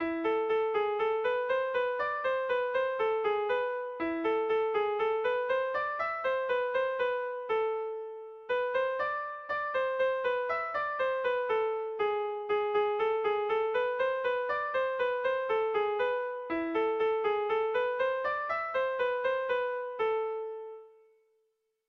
Bertso melodies - View details   To know more about this section
Kontakizunezkoa
Kasu honetan ere doinu zaharra berria baino etenaldi gutxiagoz dabil
Hamarreko txikia (hg) / Bost puntuko txikia (ip)
A1A2BDA2